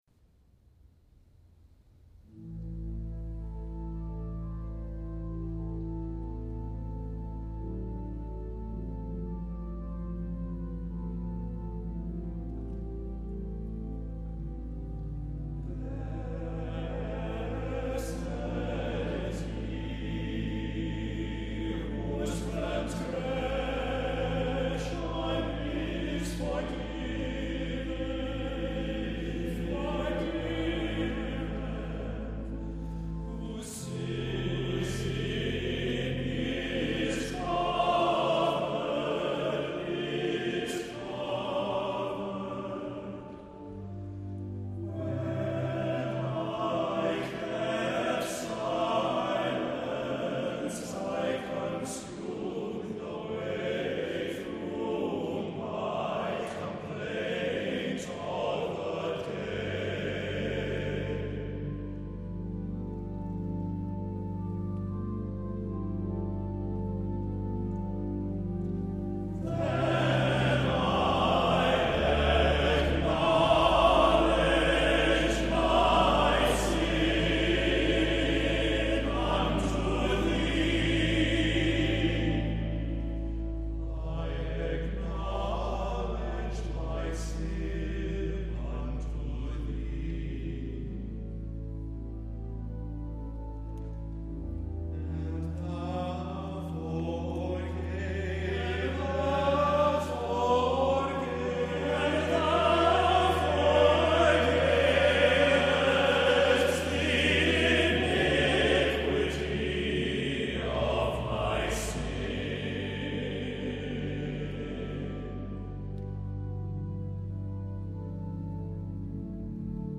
• accompaniment works very well for either piano or organ
• reminiscent of the 19th century German Romantic partsongs